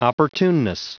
Prononciation du mot opportuneness en anglais (fichier audio)
Prononciation du mot : opportuneness